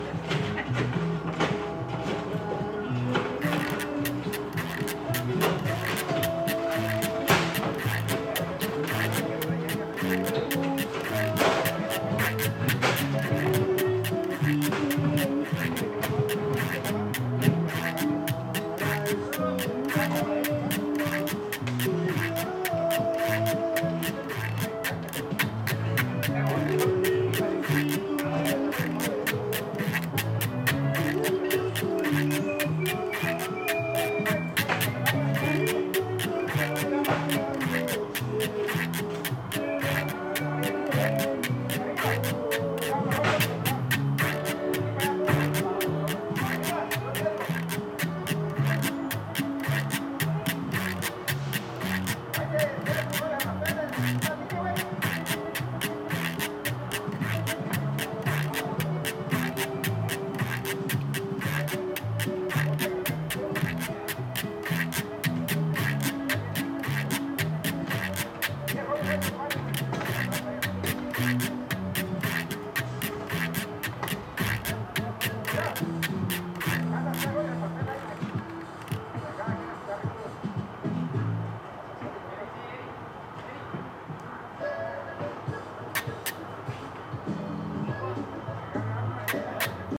amigo lullaby 〜アミーゴ達のララバイ / 環境音🎺🥁🎻🚧👷‍♂👷‍♀🪗🪇🪘豆日記📓